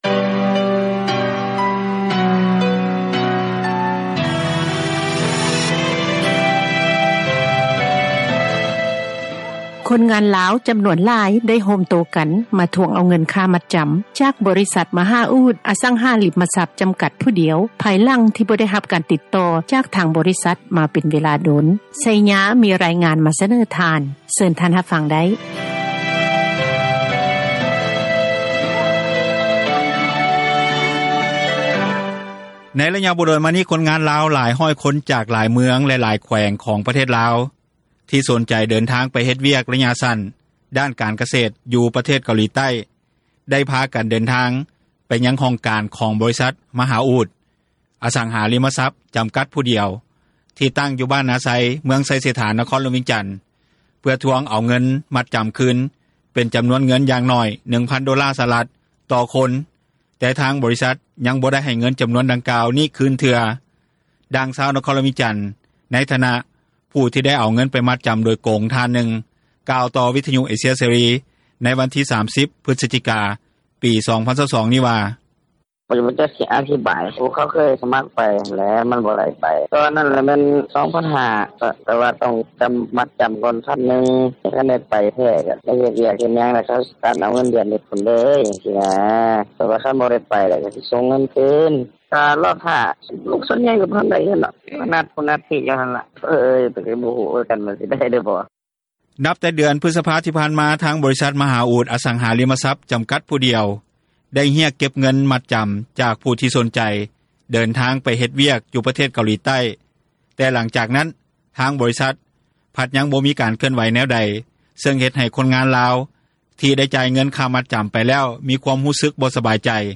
ດັ່ງຊາວບ້ານ ຢູ່ເມືອງໄຊເສດຖາ ນະຄອນຫລວງວຽງຈັນ ນາງນຶ່ງກ່າວຕໍ່ວິທຍຸ ເອເຊັຽ ເສຣີ ໃນມືືຶ້ດຽວກັນນີ້ວ່າ:
ດັ່ງຄົນງານລາວ ທີ່ຢູ່ລະຫວ່າງການລໍຖ້າ ເພື່ອເດີນທາງໄປເຮັດວຽກ ໄລຍະສັ້ນຢູ່ປະເທດເກົາຫລີໃຕ້ ນາງນຶ່ງກ່າວວ່າ: